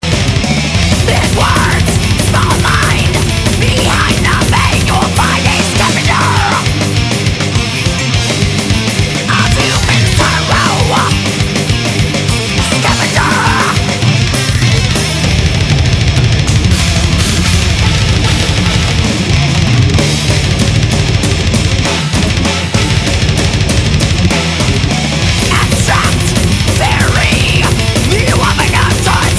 All the samples on this page are 22khz/44khz,16bit,stereo.
guitar solo